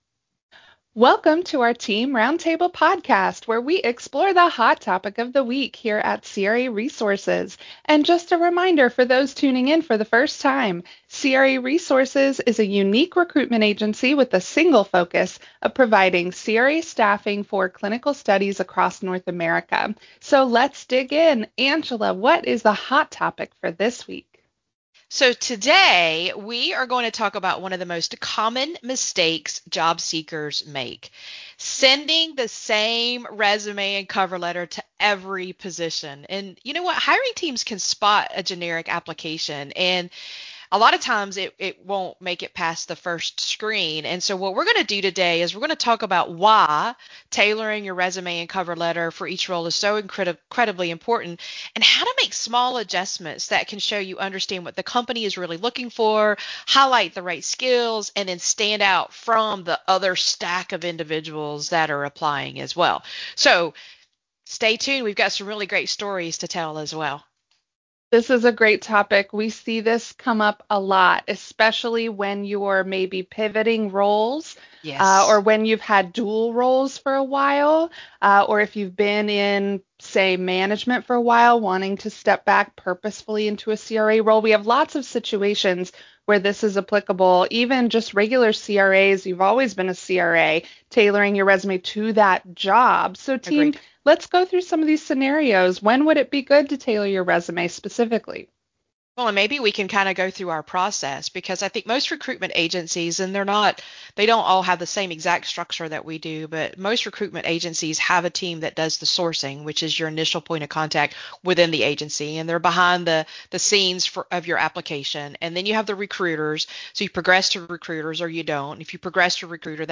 Roundtable: #1 Resume Rule - craresources
Our team also discusses the recruitment process stages and why this rule matters at each step—from initial resume screening to final hiring manager review.